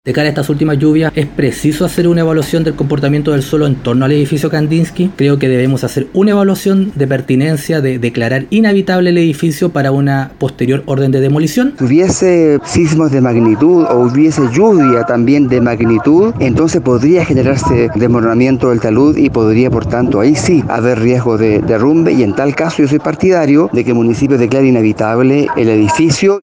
Por su parte, los concejales Pablo González y René Lues recalcaron la opción de declarar inhabitable el edificio Kandinsky, para proceder a su demolición.